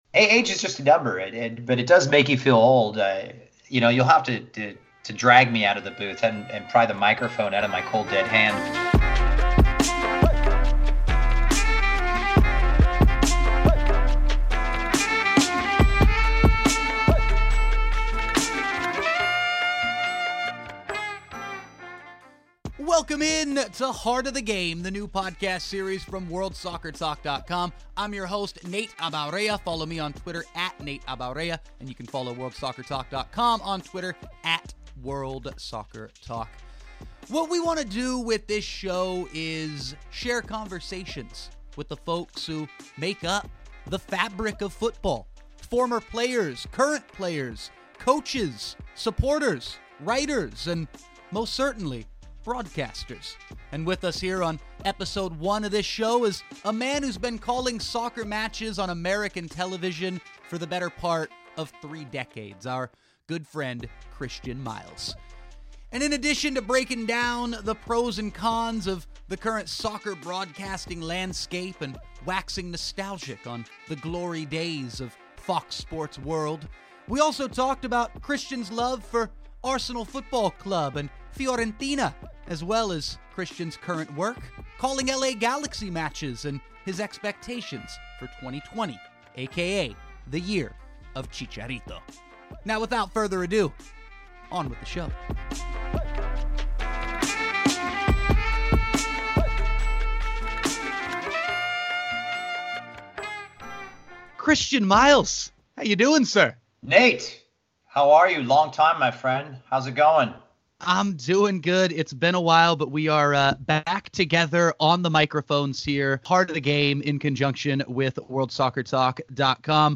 The Heart of The Game is a brand-new weekly podcast featuring interviews with the studio talent, commentators, players and coaches that bring us the beautiful game.